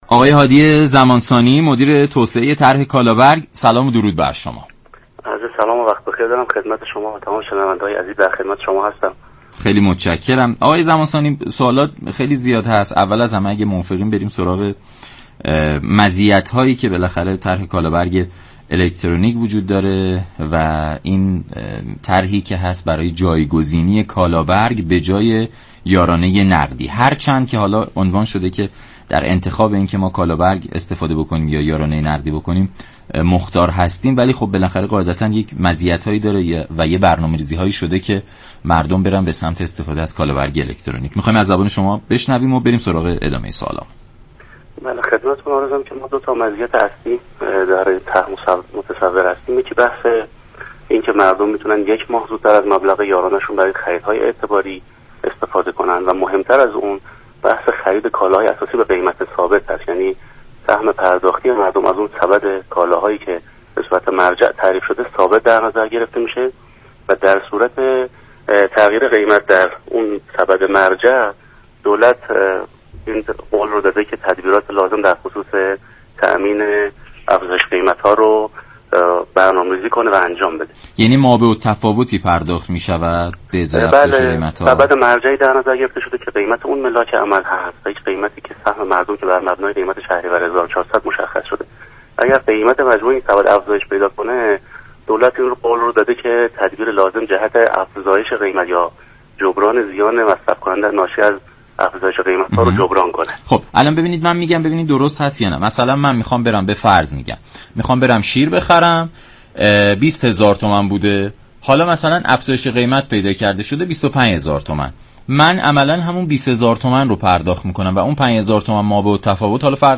برنامه «نمودار» شنبه تا چهارشنبه هر هفته ساعت 10:20 از رادیو ایران پخش می شود.